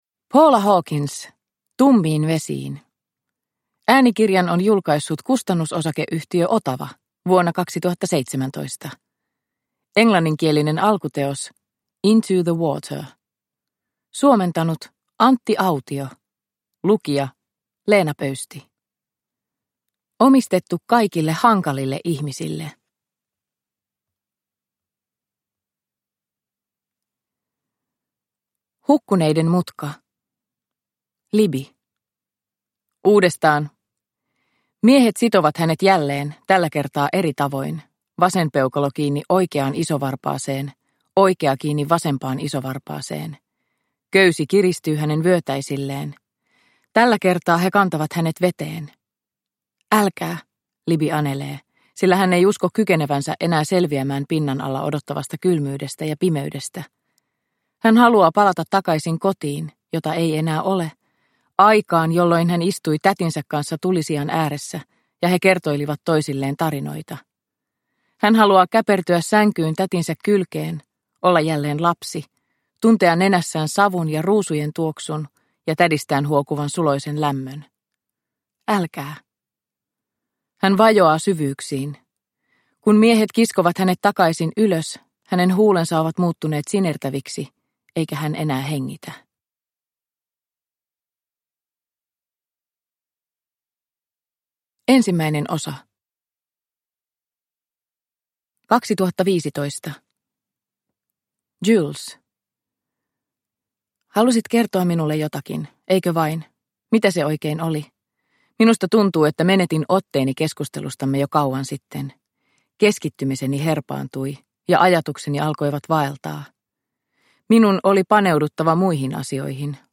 Tummiin vesiin – Ljudbok – Laddas ner